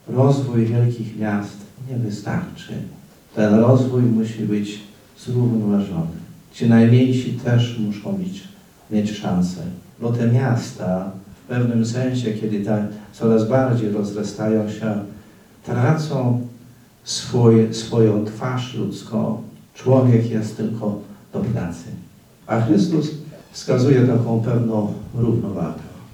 W Starostwie Powiatowym w Łomży odbyło się dziś spotkanie opłatkowe.
Biskup łomżyński, Janusz Stepnowski podkreślał, że Polska opiera się głównie na mniejszych miastach i miasteczkach i ważne jest to, aby nowy rząd o tym pamiętał.